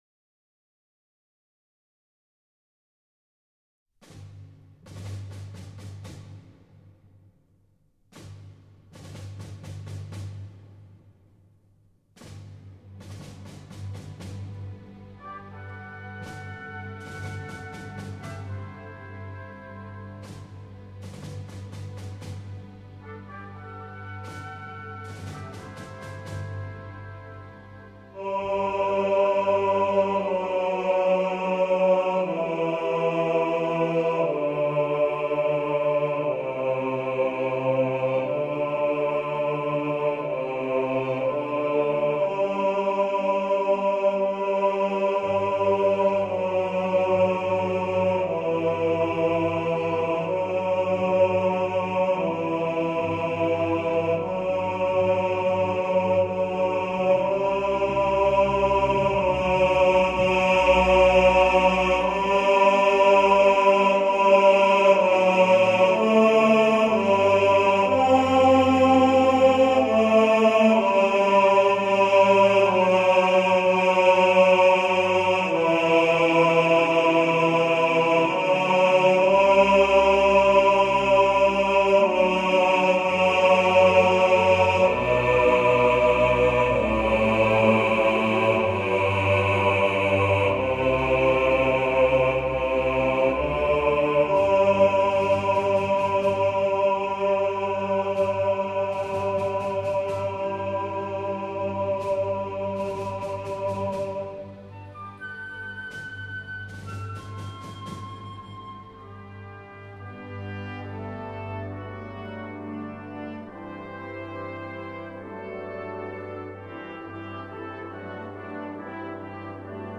Hymn To The Fallen – Bass | Ipswich Hospital Community Choir